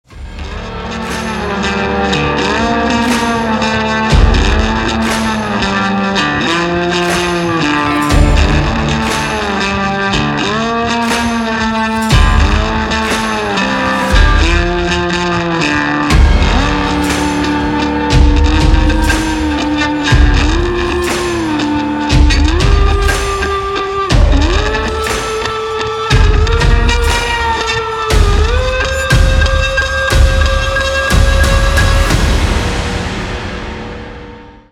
• Качество: 320, Stereo
красивые
без слов
инструментальные
тревожные
dark ambient
Bass
эпичные
dark rock